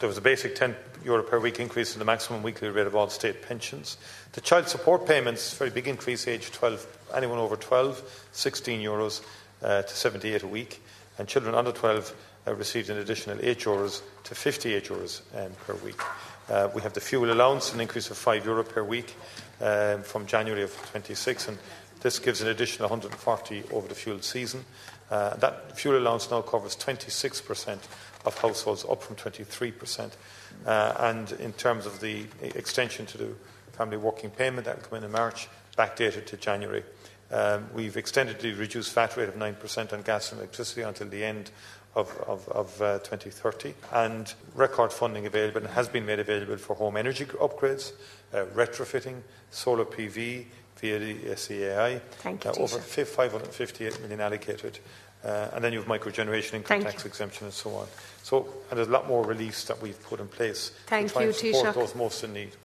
In response, the Taoiseach outlined measures that can be availed of: